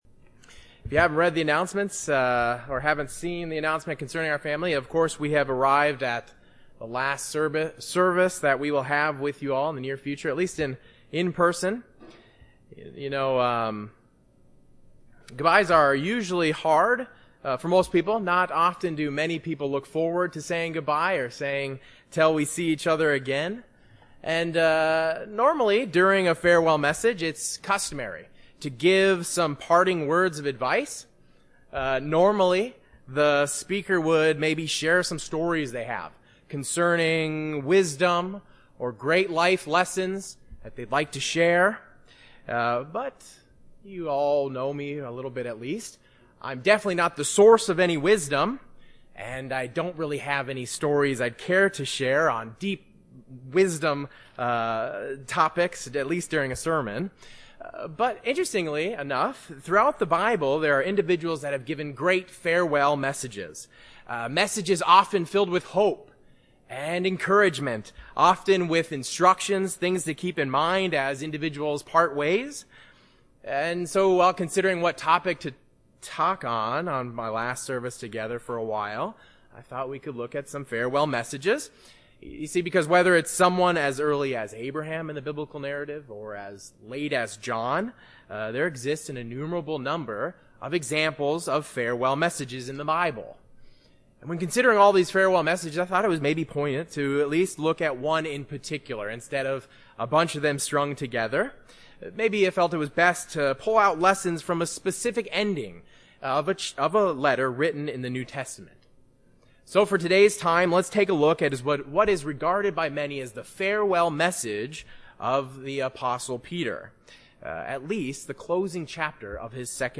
Regarded as Peter's farewell epistle, the letter Second Peter holds many important teachings and reminders for the Christian today. In this sermon a review is given of the final chapter of Second Peter, taking three main lessons we can learn in a farewell context.
Given in Chicago, IL